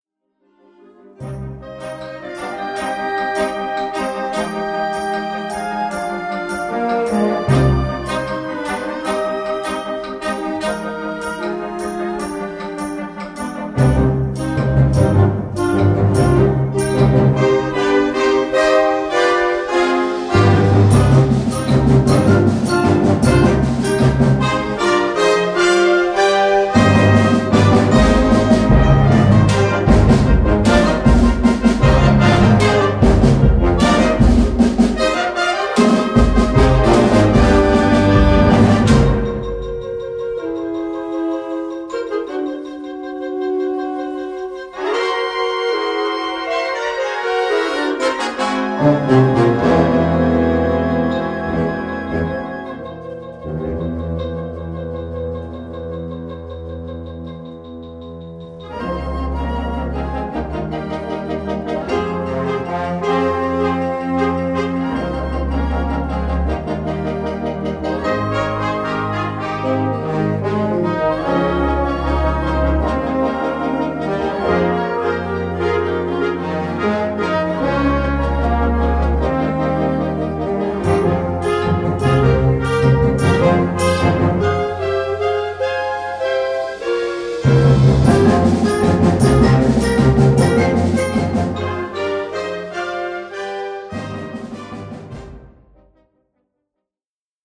Categoría Banda sinfónica/brass band
Subcategoría Música de concierto / Música sinfónica
Instrumentación/orquestación Ha (banda de música)